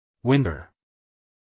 winter-us-male.mp3